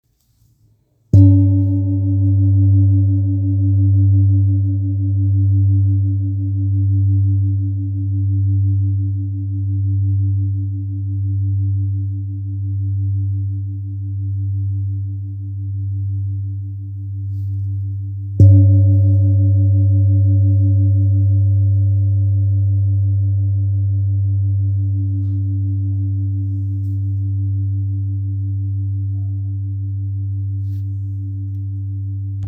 Kopre Singing Bowl, Buddhist Hand Beaten, Antique Finishing, Select Accessories, 18 by 18 cm,
Material Seven Bronze Metal